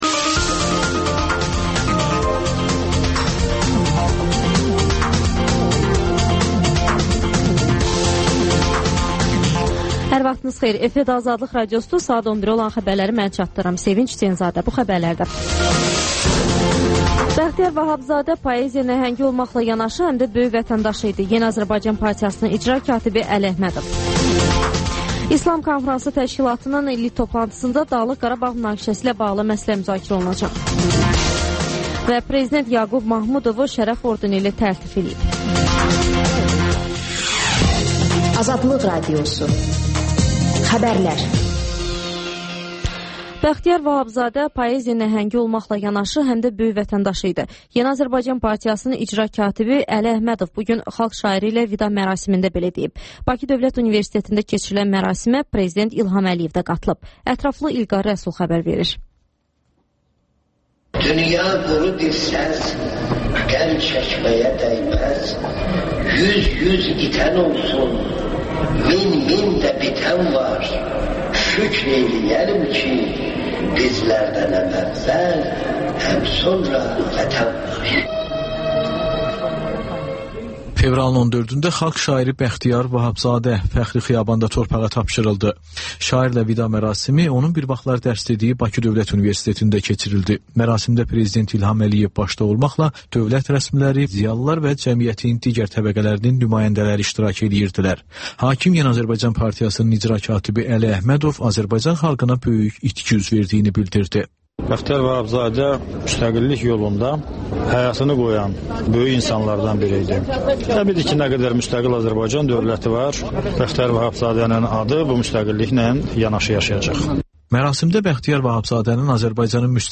Azərbaycan yazarları sizə öz əsərlərini təqdim edir Təkrar